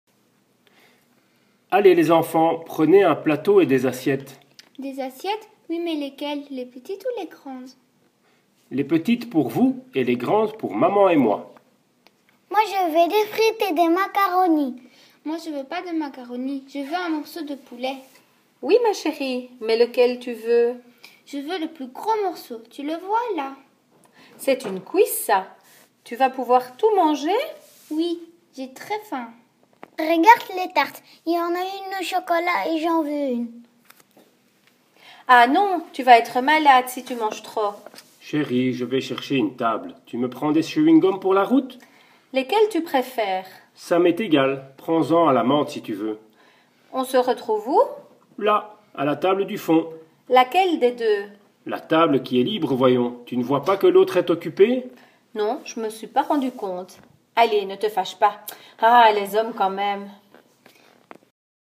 À la cafétéria d'une autoroute en Belgique, une famille choisit ce qu'elle va manger.
famille-cafeteria.mp3